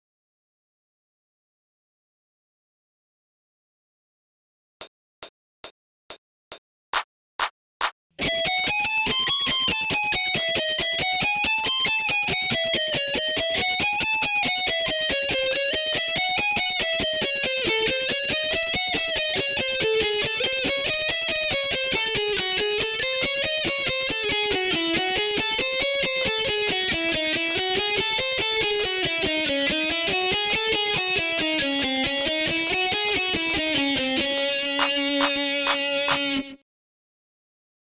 training phrase 7　ハーモニックマイナースケール〜下降フレーズ〜
training phrase 5と同じ下降フレーズですが、ハーモニックマイナースケールのため、若干フレットポジションがややこしく感じるかも知れません。